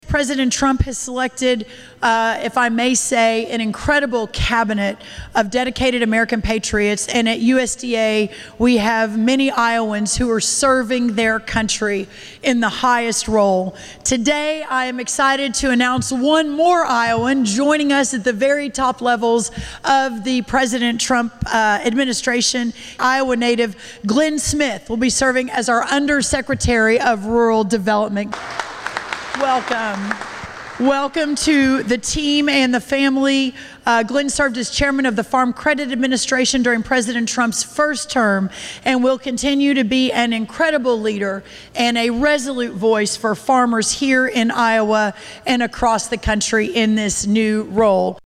Rollins made the announcement Saturday afternoon during the Governor’s Steer Show at the Iowa State Fair.